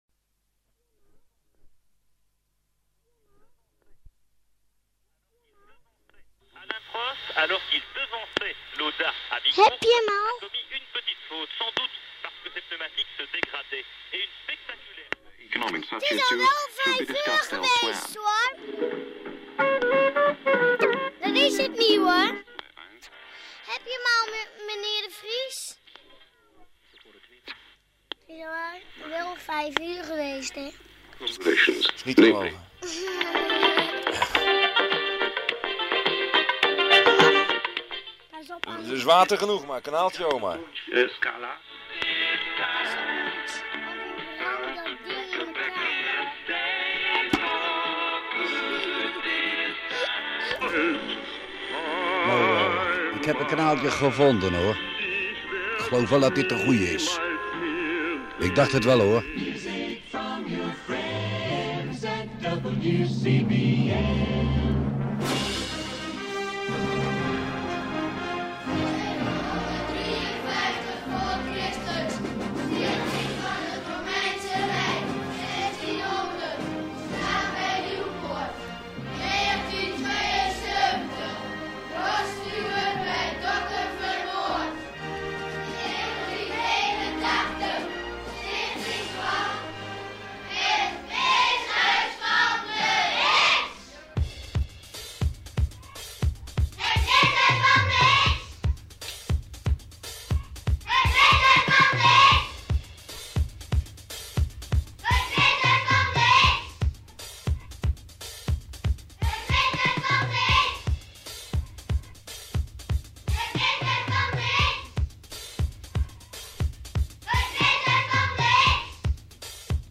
Weeshuis van de Hits – Stranduitzending
1984-Stranduitzending.mp3